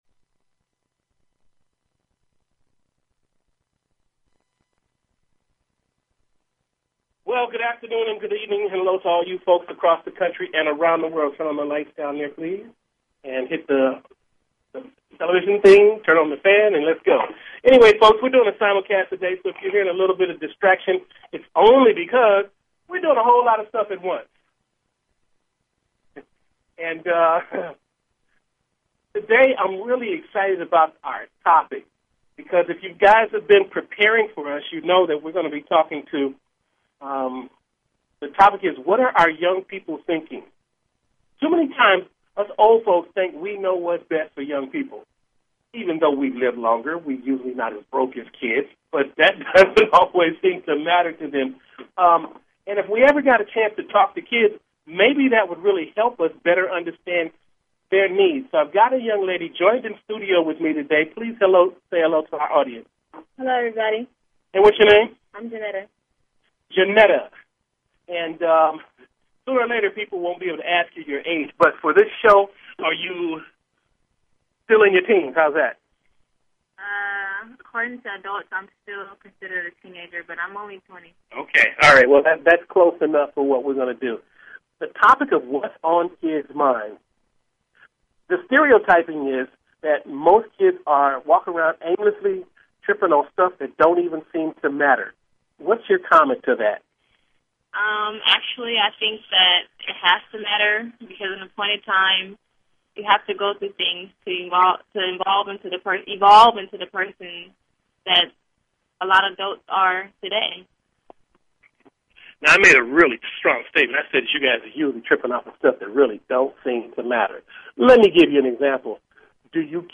Talk Show Episode, Audio Podcast, Grassroots_Talks and Courtesy of BBS Radio on , show guests , about , categorized as